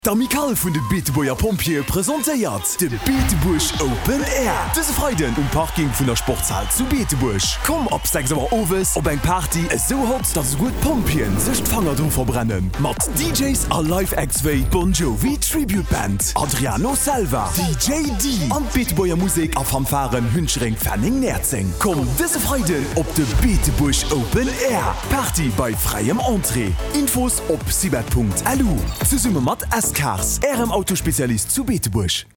Lauschtert hei de Radio-Spot op EldoRadio fir de Beetebuerg OpenAir: